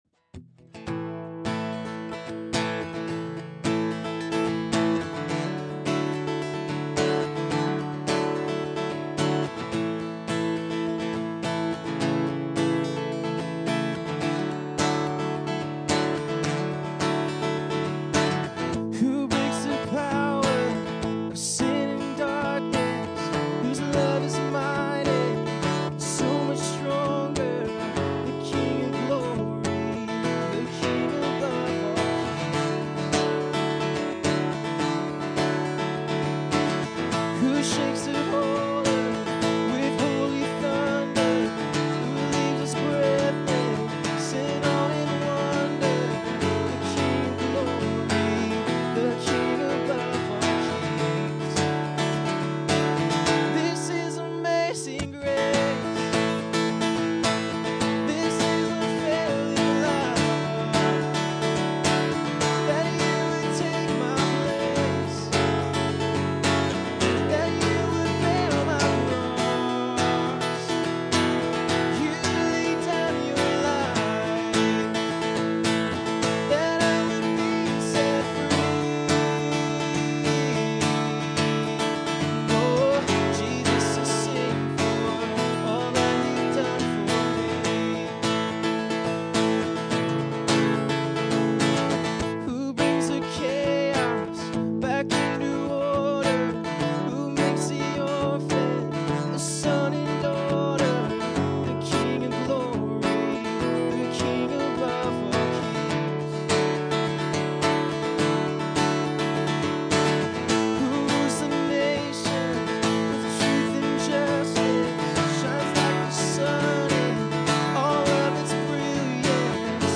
Category: Worship